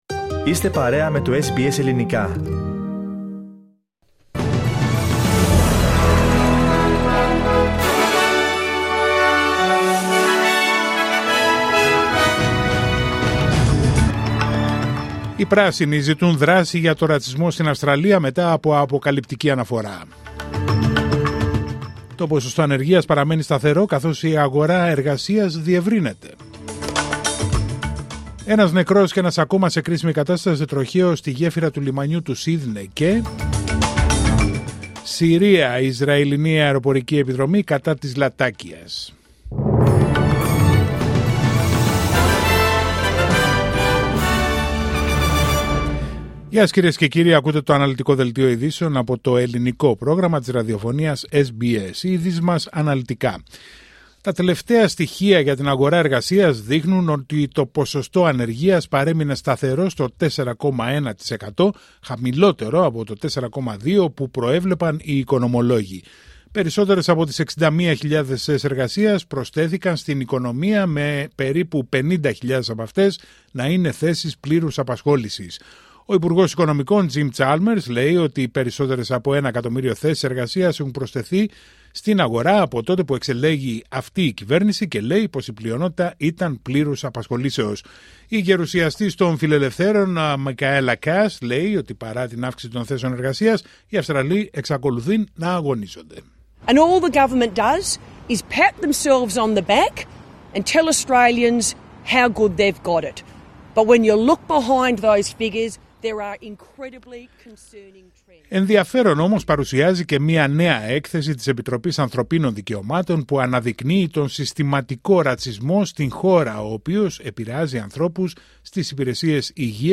Δελτίο ειδήσεων Πέμπτη 17 Οκτωβρίου 2024